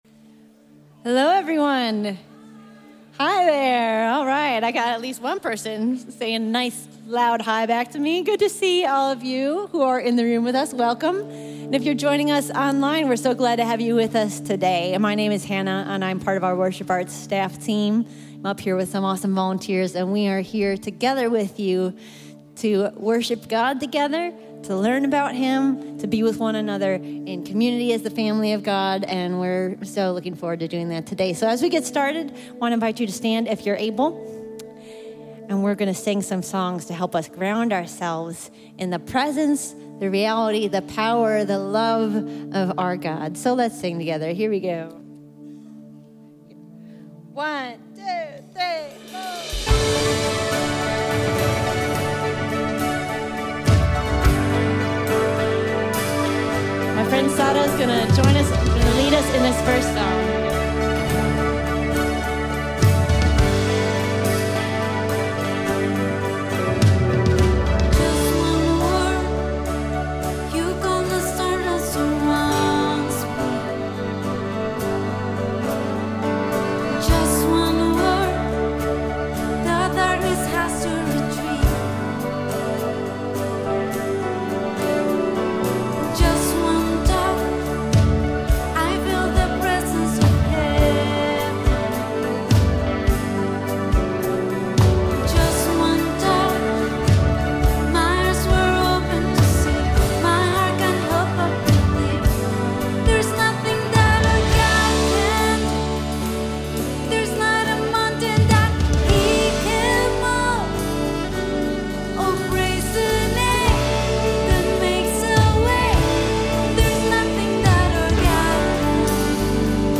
A message from the series "The Kingdom of God."